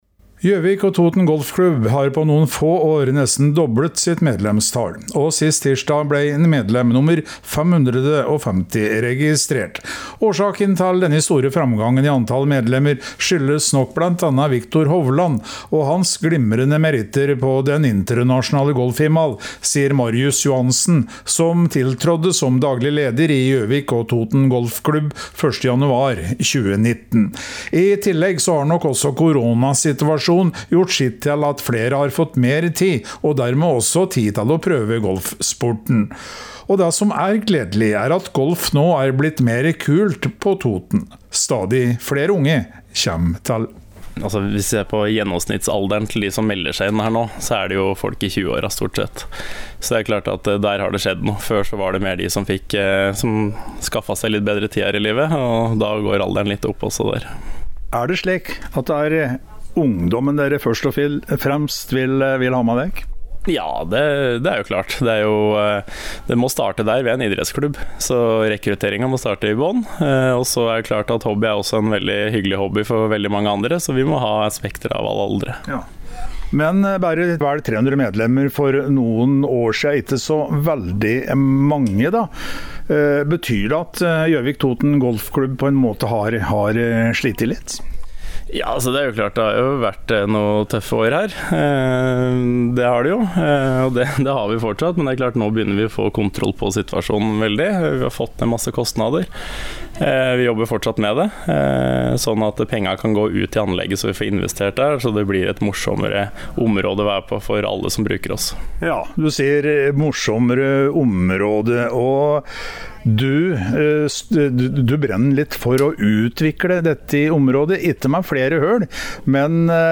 Innslag